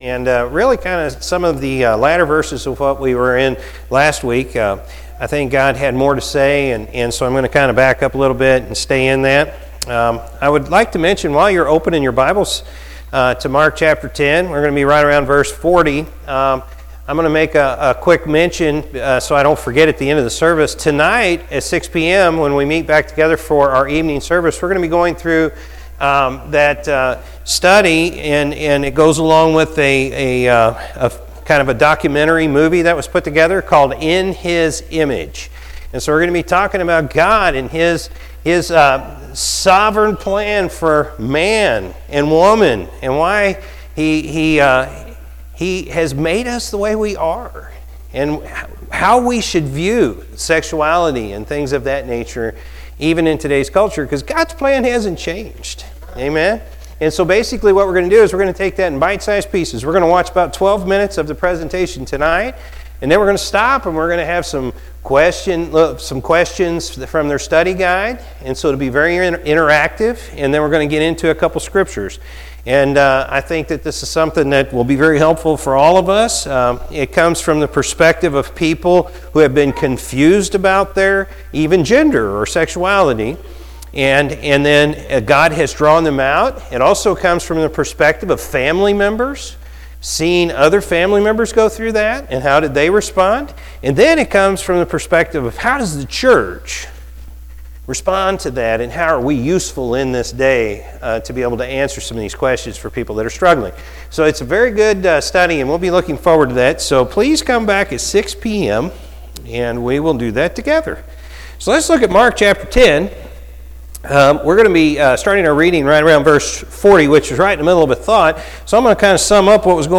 Feb-7-2021-morning-service.mp3